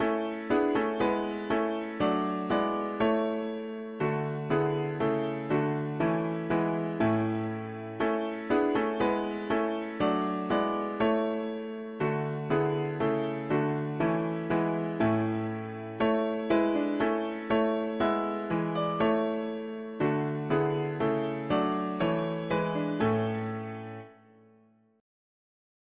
to thee we raise… english secular 4part chords
Key: A major Meter: 77.77.77